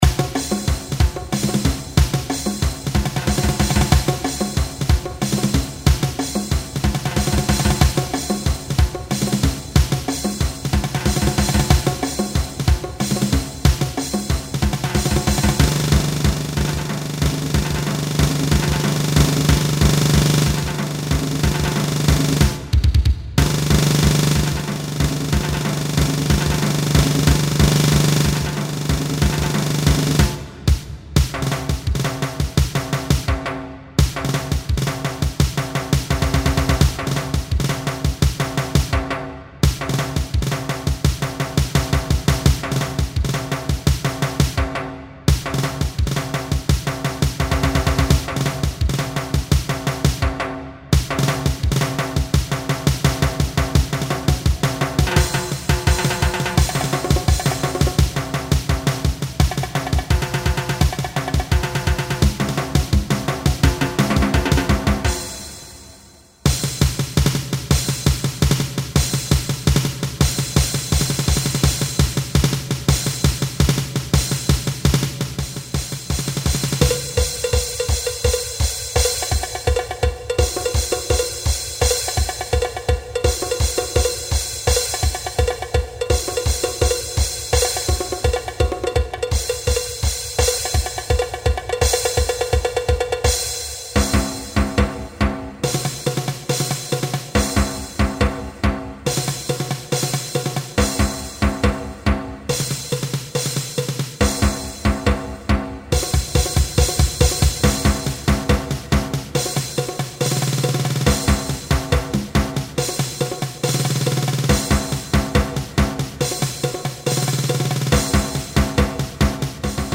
Ongestemd Marcherend Slagwerk